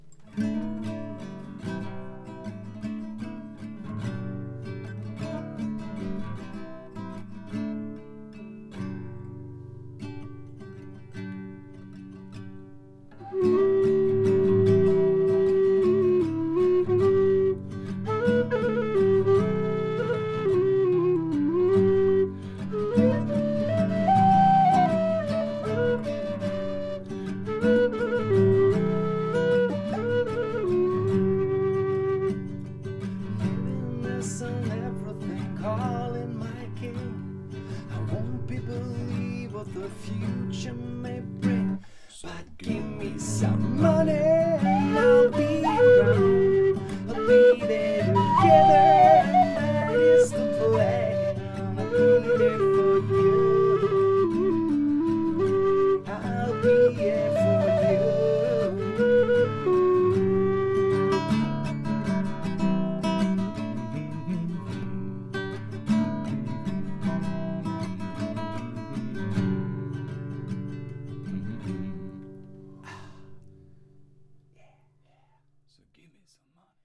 Lånade ett par mickar av polarn för att testa mot de jag brukar använda. Lite halvimprovisation med nylonsträngat och low whistle.
Andra mickparet;
Mickarna placerade mitt i rummet på en meters avstånd. Vi sitter på ca. 30cm avstånd från mickarna på en linje.